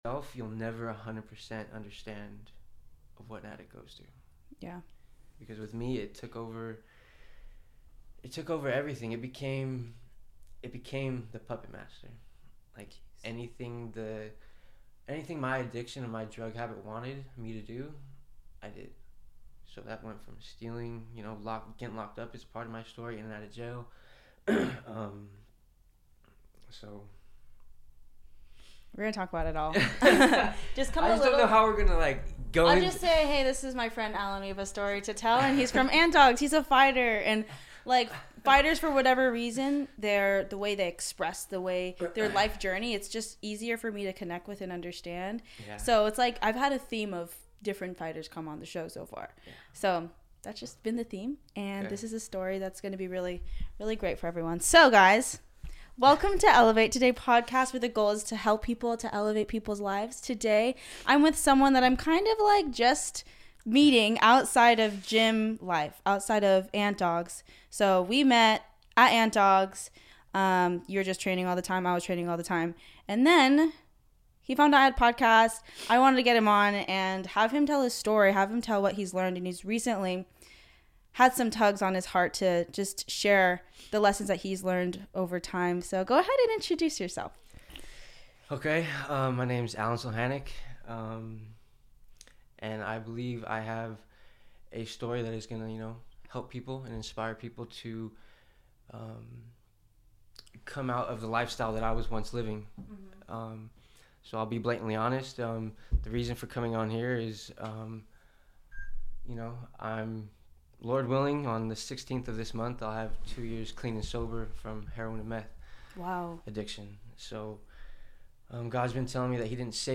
In this episode I interview